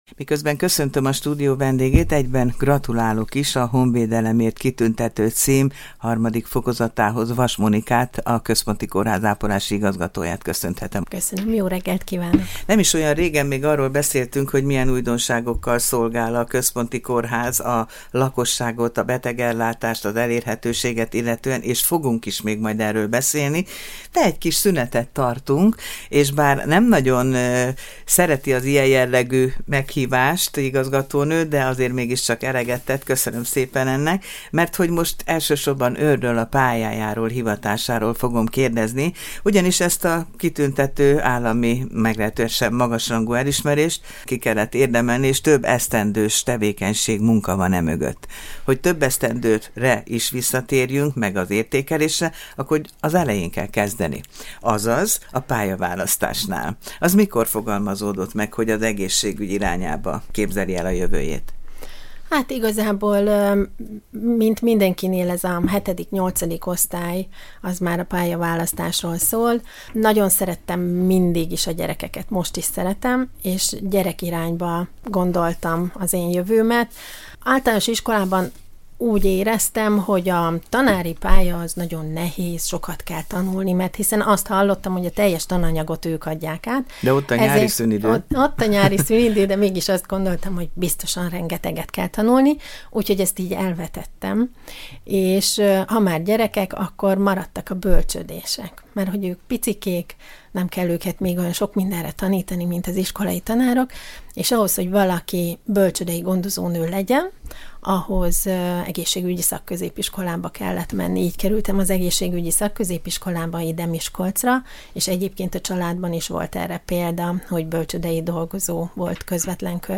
Ebből az alkalomból kérdezte választott hivatásáról a kitűnő szakembert